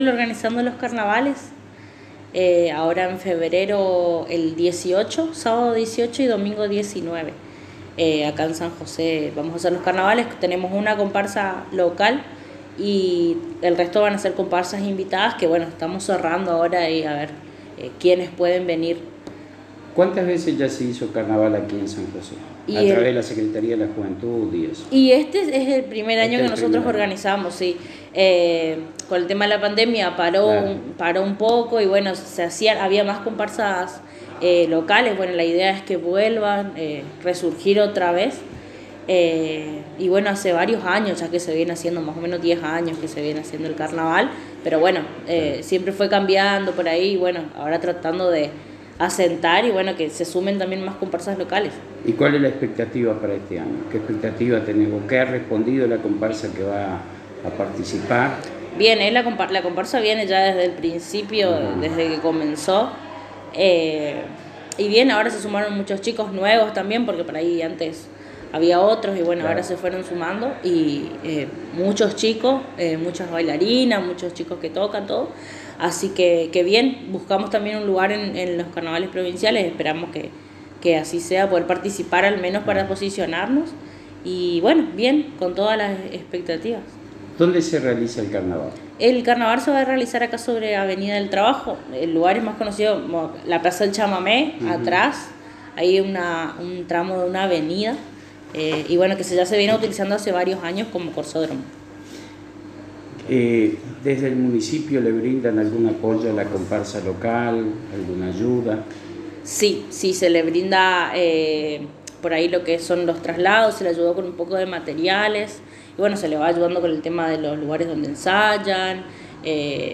Agustina Fleck Secretaria de la Juventud del Municipio de San José en diálogo exclusivo con la ANG manifestó el trabajo que están realizando en la organización de los Carnavales en su Municipio que se llevarán a cabo con la presencia de la comparsa de San José que este año ha sumado más participantes y el Municipio le ha apoyado con elementos para las ropas e instrumentos y viajes que realizaron.